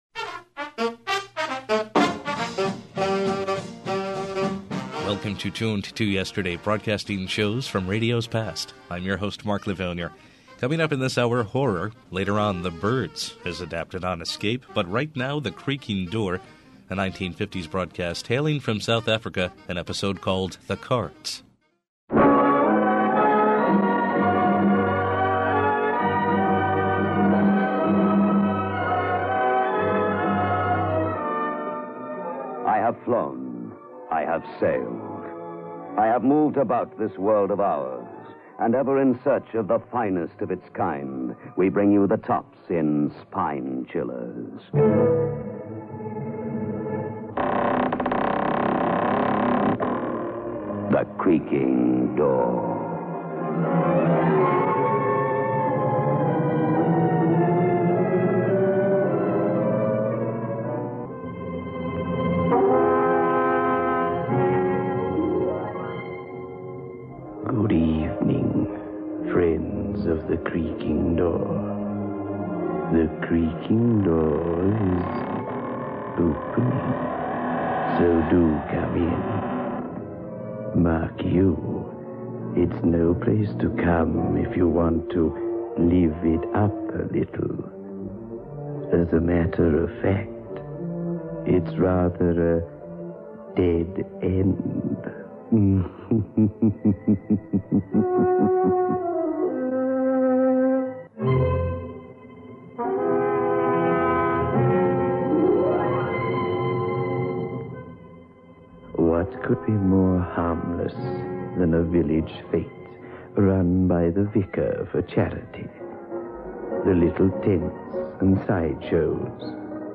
The highest quality broadcasts are restored and played as they were heard years and years ago.